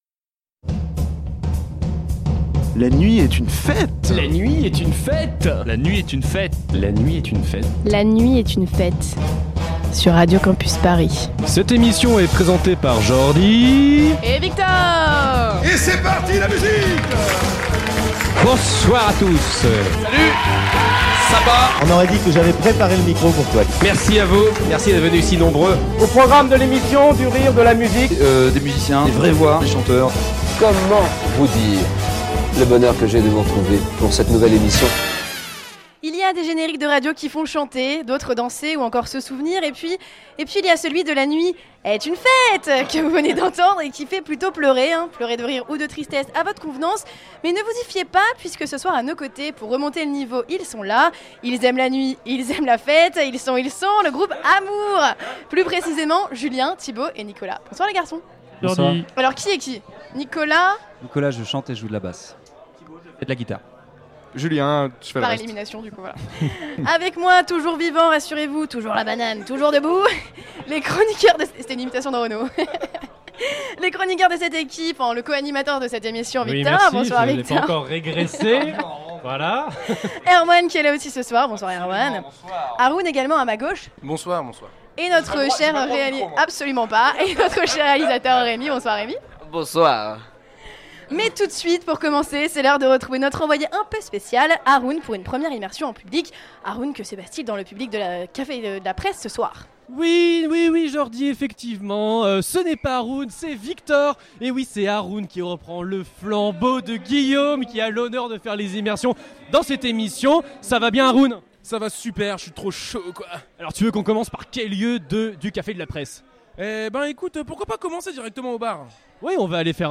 Une heure de musique live, de confessions intimes et de conversations secrètes
Du rire, du live, de l'amitié, de l'amourE (LOL), c'est La Nuit Est Une Fête !